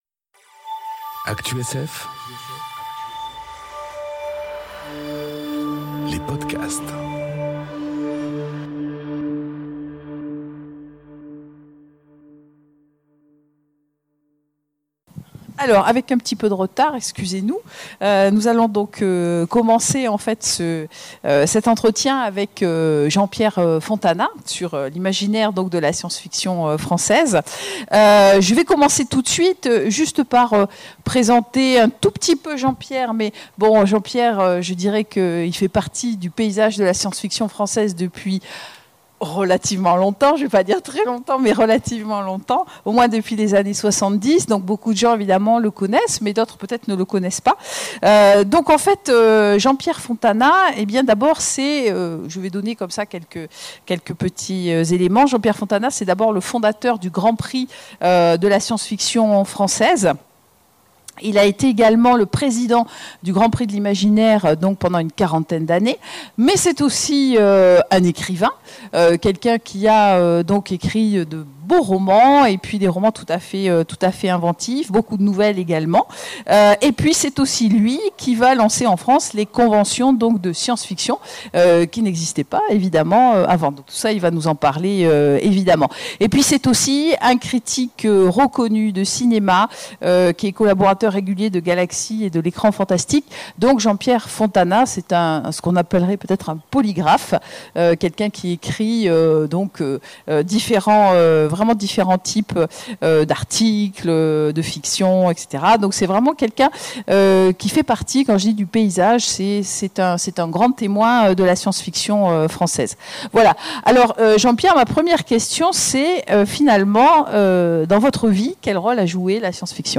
Imaginales 2019 : Ils ont fait l'imaginaire en France : entretien à coeur ouvert